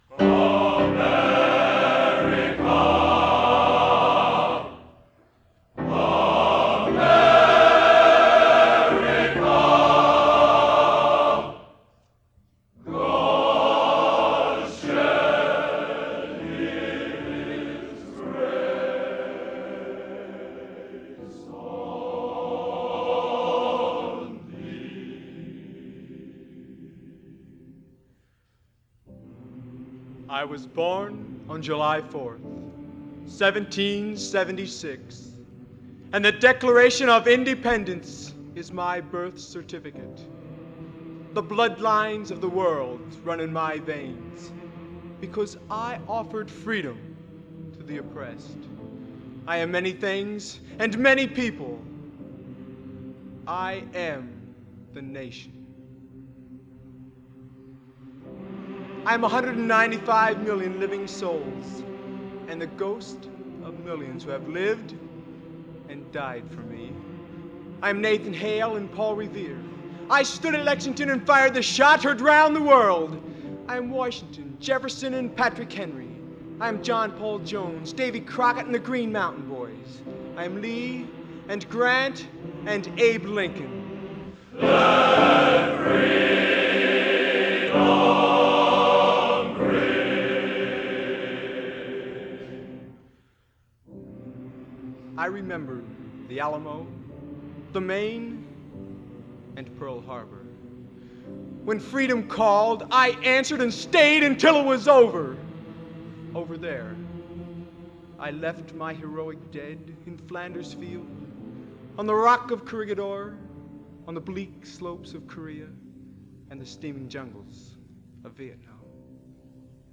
Genre: Original Composition Patriotic | Type: End of Season